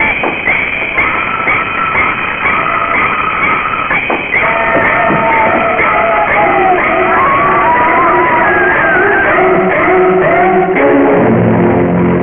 Listen to the soothing sounds as the chief surgeon makes the first incision.